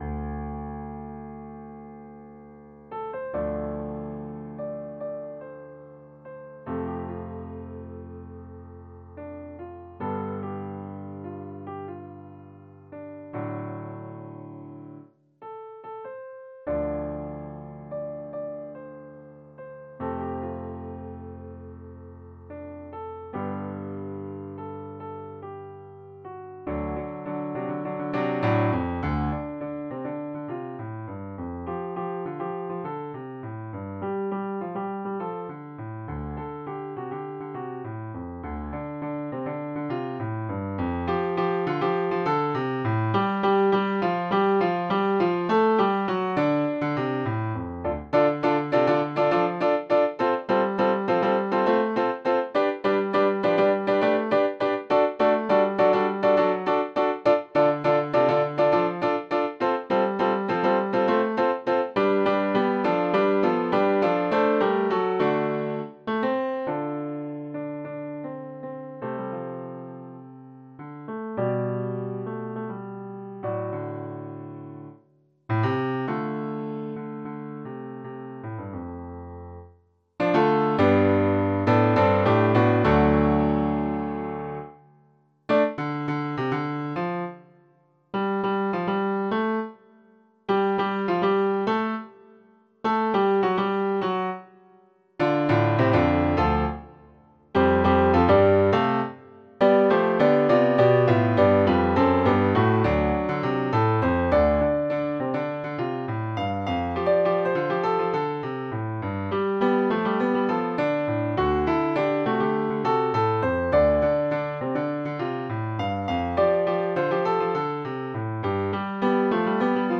SATB
MIDI rendering of full score:
TalkingToThePeople-choral-Dm-RehearsalScore.mp3